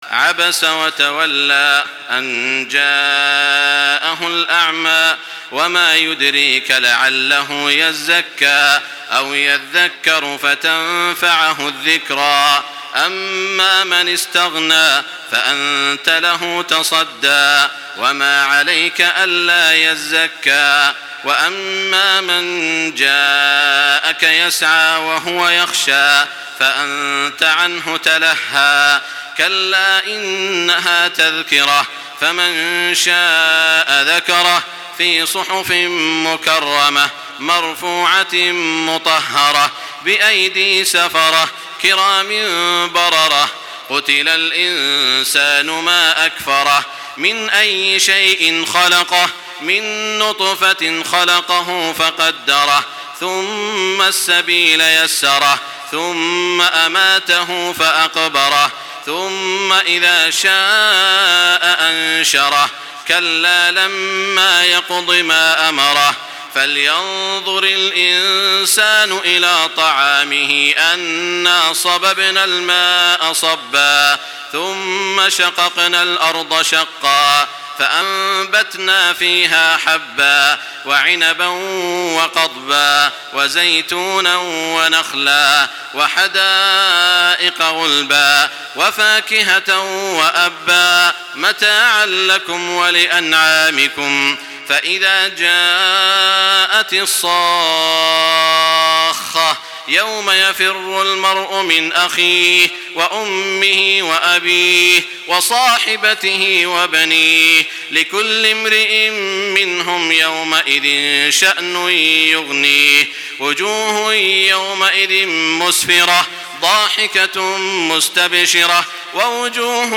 Surah Abasa MP3 by Makkah Taraweeh 1425 in Hafs An Asim narration.
Murattal